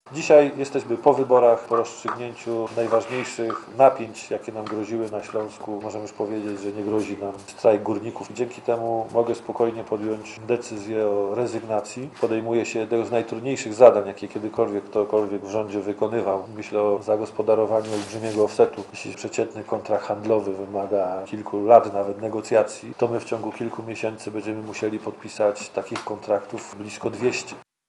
Posłuchaj co powiedział Szarawarski